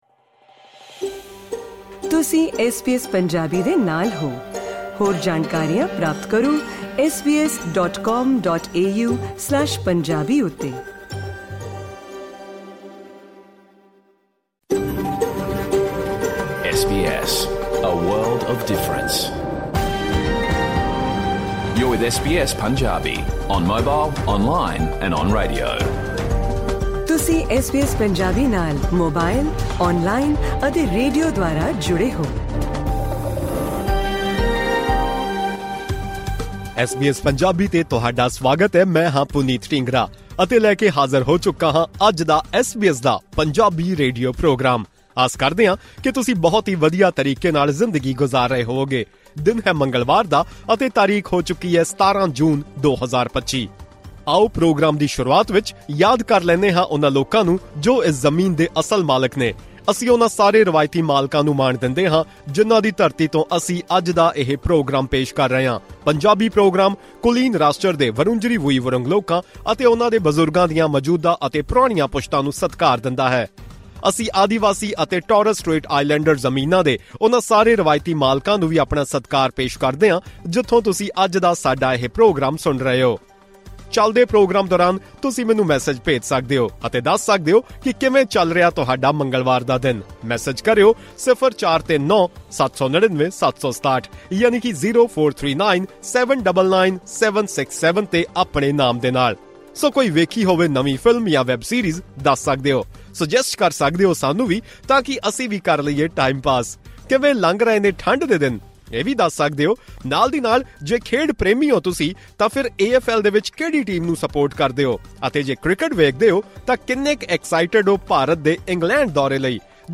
In today’s SBS Punjabi radio program, we discussed 'Kisan' Dairy Products, started by Punjabi brothers in Australia, which won 6 Gold, 9 Silver medals, and the Highest Scoring Award at the Dairy Industry Association of Australia Awards. Additionally, a report on 'genocide' was presented. Along with this, listen to the day’s news bulletin and updates from Pakistan in our full radio program.